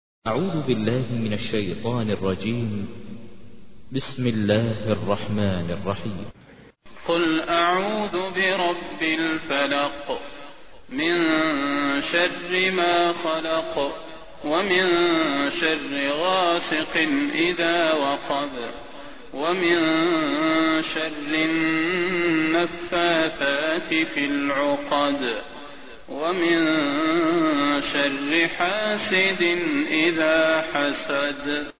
تحميل : 113. سورة الفلق / القارئ ماهر المعيقلي / القرآن الكريم / موقع يا حسين